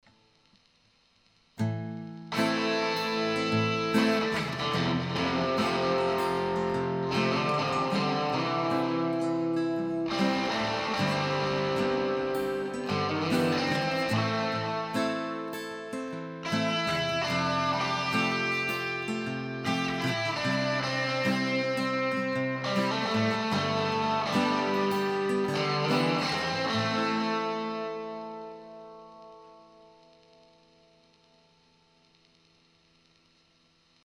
I think I can detect some good toneality, but I think better mic placement would help in the future. I like the dirty channels, but again, really bright.